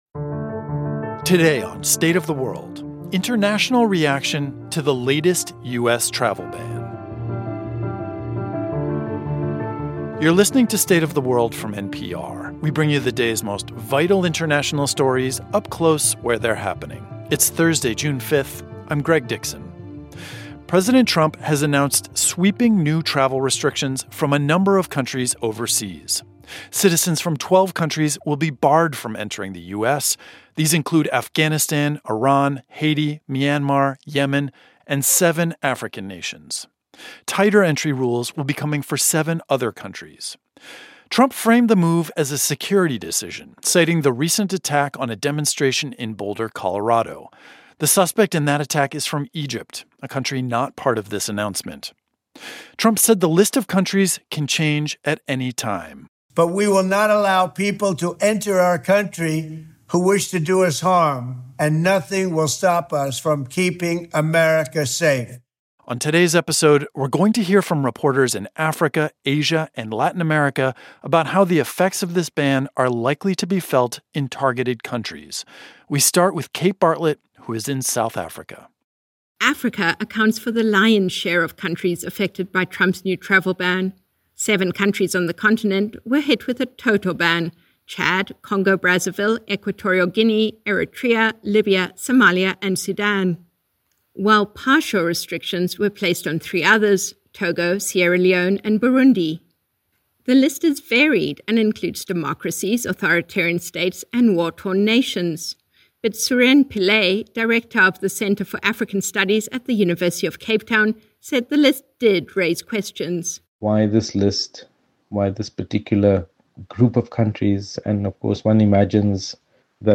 President Donald Trump has issued a new travel ban, barring travelers from 12 countries and partially restricting travelers from seven others from coming to the U.S. We hear from reporters in Asia, Latin America and Africa to hear how targeted countries might be affected.